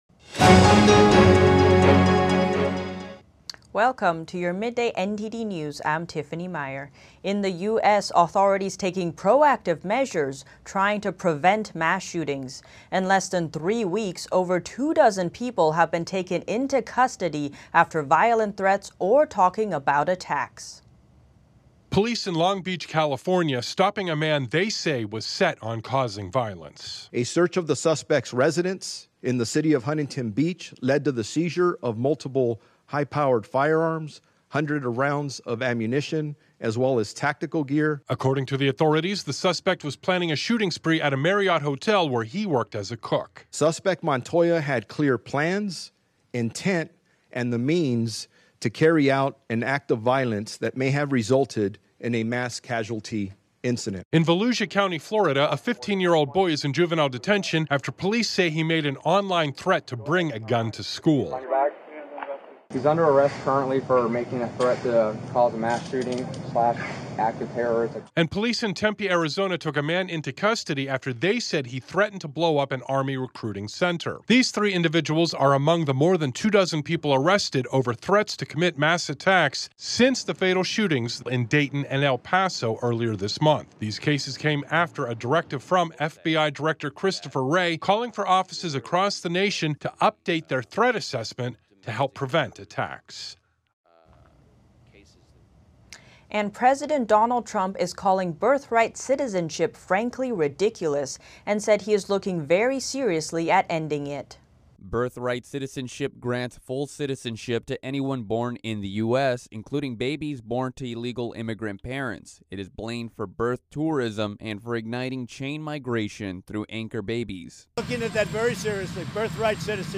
NTD News Today Full Broadcast (Aug. 22)